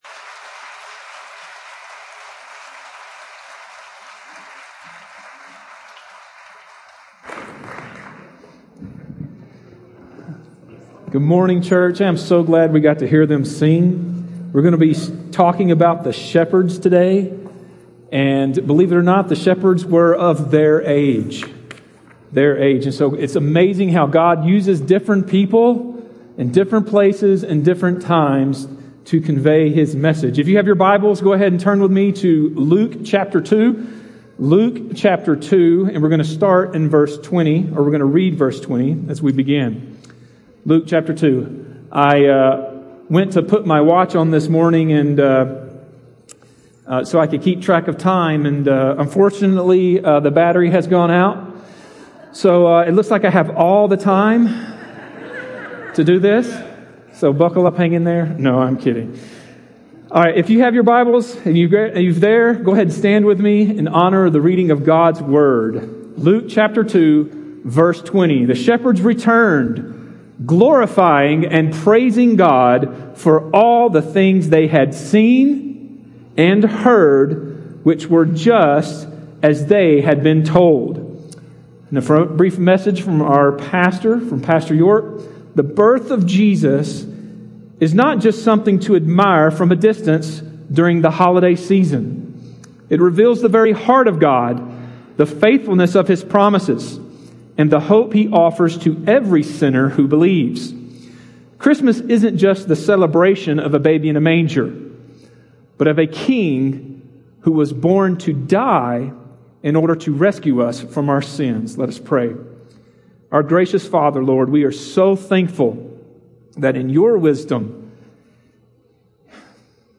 A message from the series "The Birth of a King."